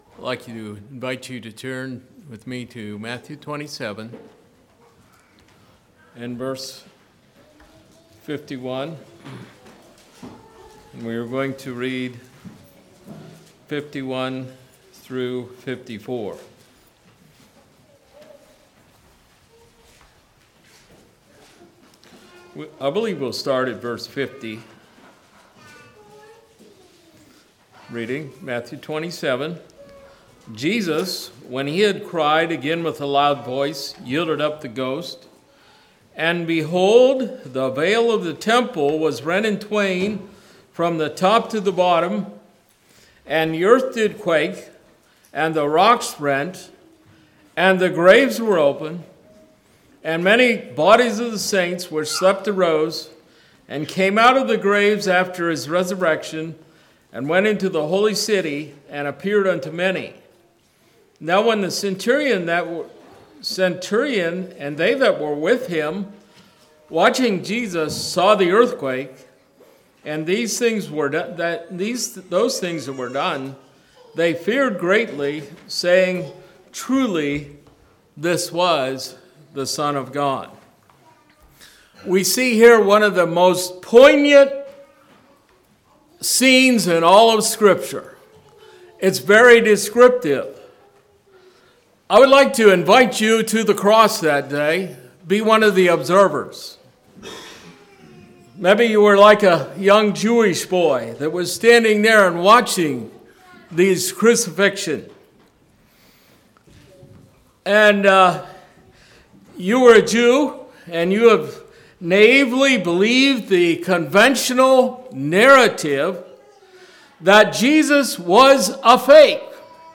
Communion message